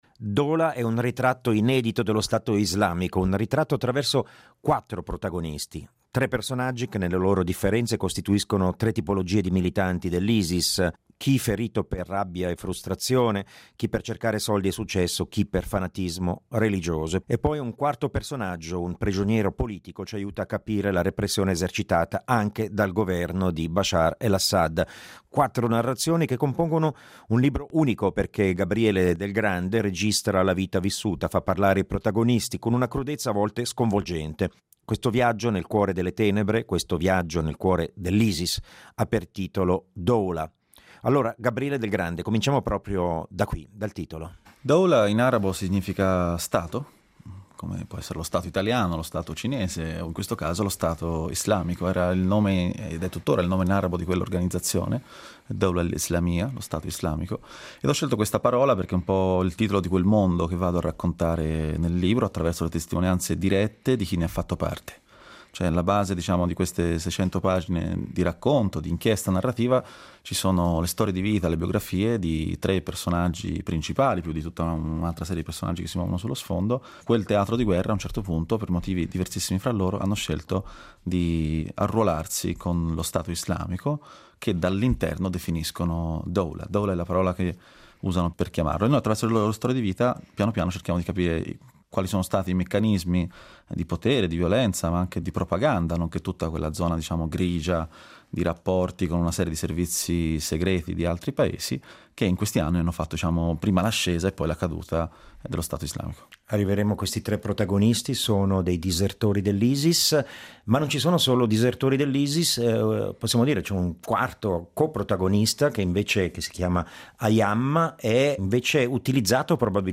nell’ambito di una serata dibattito organizzata dall’associazione gruppo Harraga